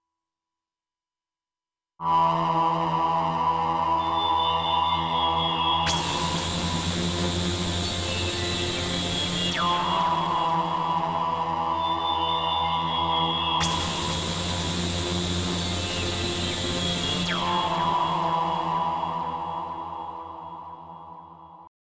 62 BPM
Ominous dungeon crawl
E phrygian haunting voice over deep drone, half-step tension